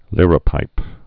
(lîrə-pīp)